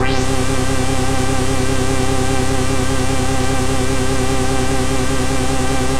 Index of /90_sSampleCDs/Trance_Explosion_Vol1/Instrument Multi-samples/Scary Synth
G2_scary_synth.wav